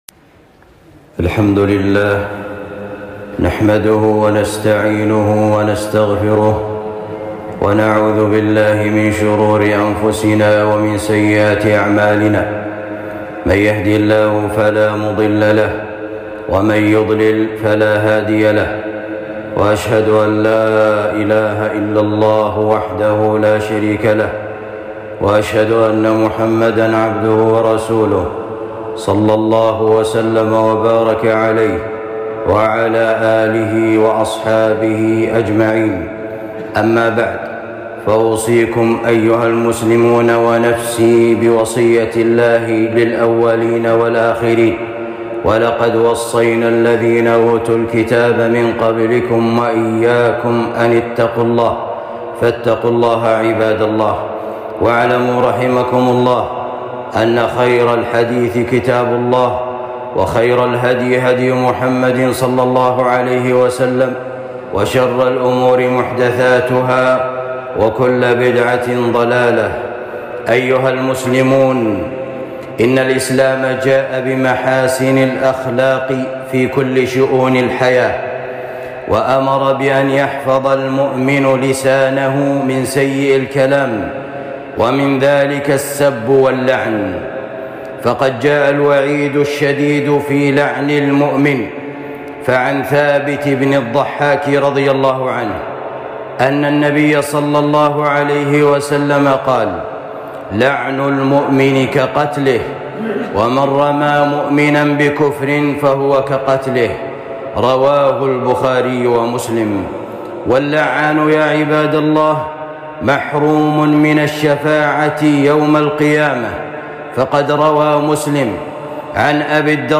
خطبة بعنوان خطورة اللعن والسب